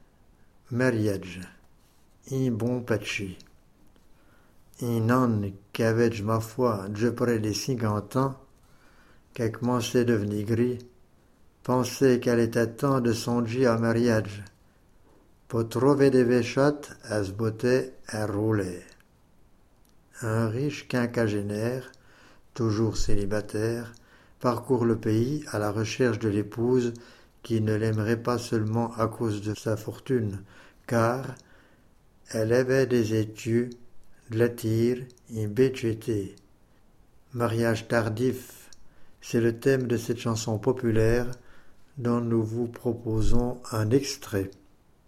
Po trovaie des baichattes è s’boté ai rôlaie.} Un riche quinquagénaire, toujours célibataire, parcourt le pays à la recherche de l’épouse qui ne l’aimerait pas seulement à cause de sa fortune, car ... {Èl aivaît des étius, d’lai tiere, ïn bé tchété.} Mariage tardif, c’est le thème de cette chanson populaire dont nous vous proposons un extrait.